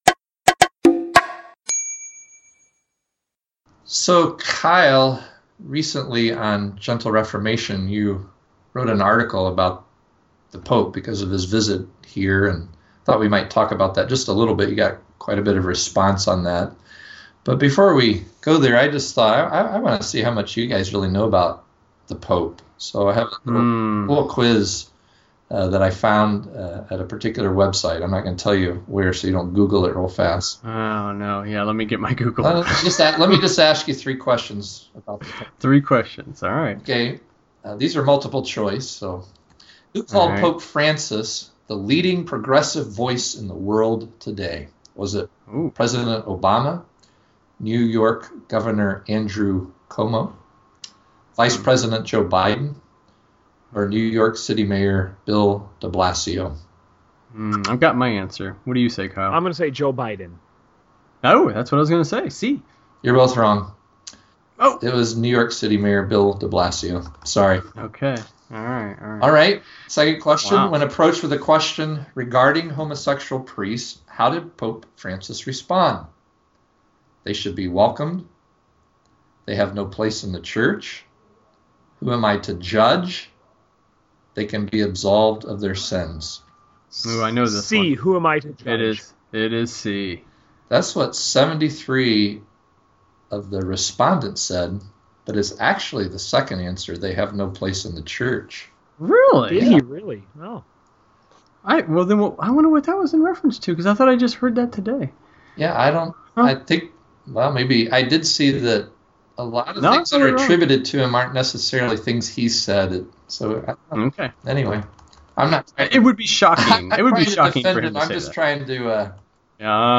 Pope Francis’ visit to America has captured the attention of the world. With microphones in hand, the men of 3GT briefly weigh in, questioning not only the apparent humility of the pope, but the very foundation of the papacy itself.